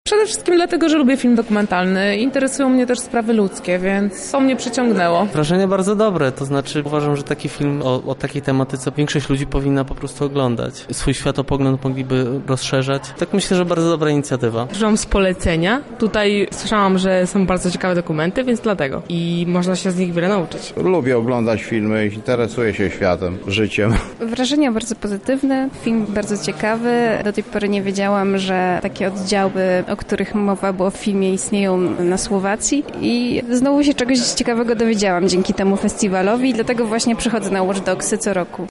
Razem z widzami przywileje obywatela poznawała nasza reporterka